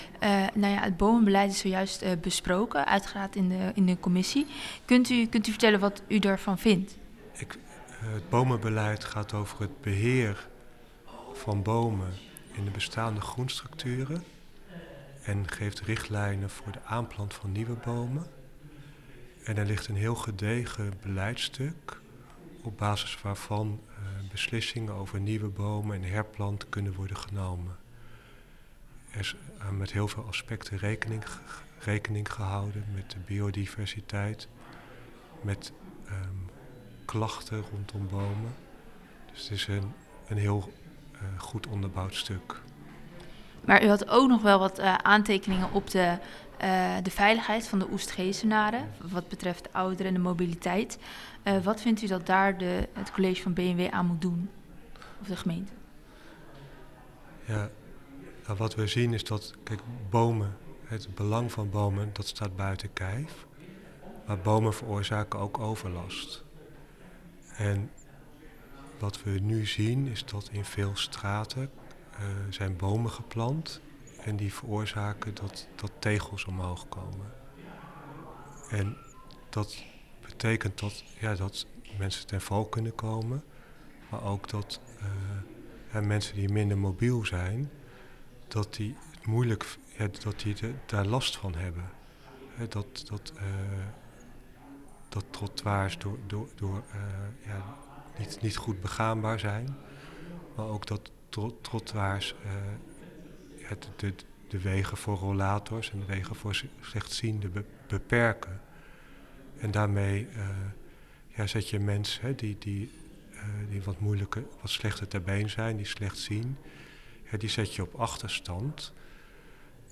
Hart voor Oegstgeest-Raadslid Sef Baaijens over het bomenbeleid.